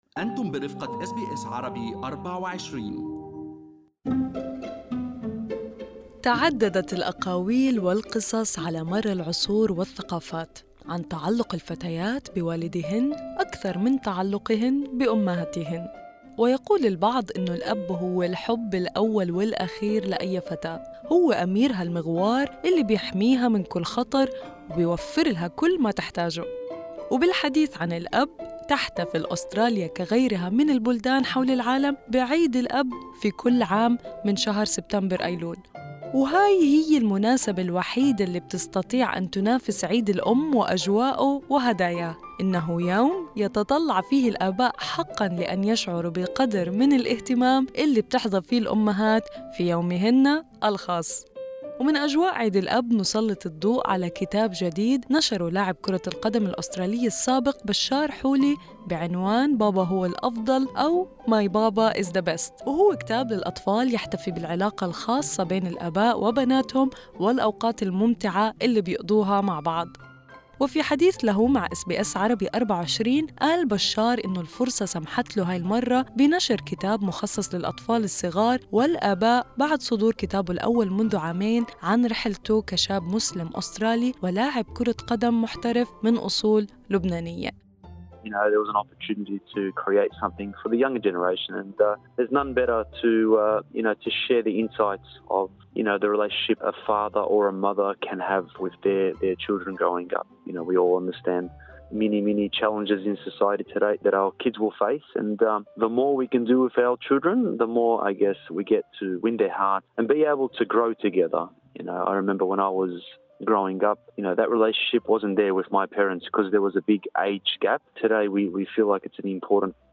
In an interview with SBS Arabic24, Bachar Houli discusses his latest book dedicated to children and parents, after publishing his first book about his personal journey as a young Australian Muslim and a professional AFL player of Lebanese origins.